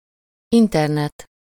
Ääntäminen
Ääntäminen Tuntematon aksentti: IPA: /ˈintɛrnɛt/ Haettu sana löytyi näillä lähdekielillä: unkari Käännöksiä ei löytynyt valitulle kohdekielelle.